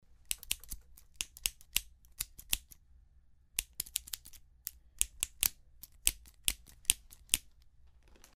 Подборка включает четкие и реалистичные записи, которые подойдут для видео, подкастов или звукового оформления.
Звук клацанья ножниц